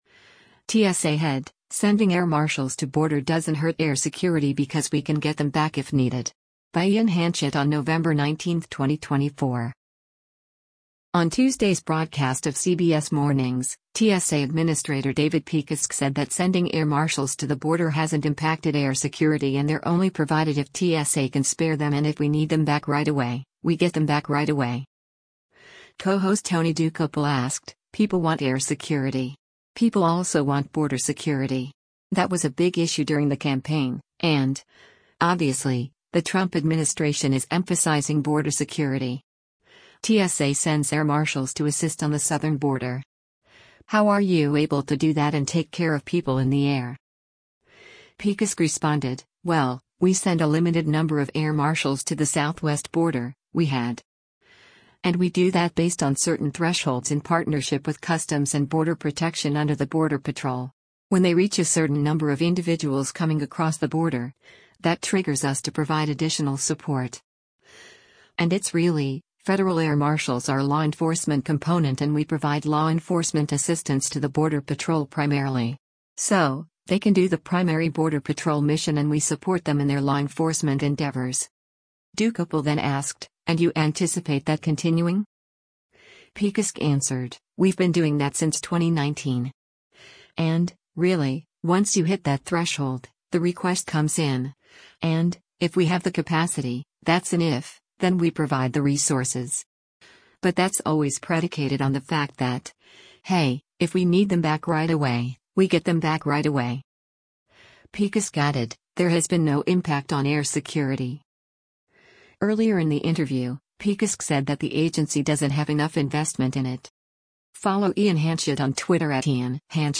On Tuesday’s broadcast of “CBS Mornings,” TSA Administrator David Pekoske said that sending air marshals to the border hasn’t impacted air security and they’re only provided if TSA can spare them and “if we need them back right away, we get them back right away.”
Earlier in the interview, Pekoske said that the agency doesn’t have enough investment in it.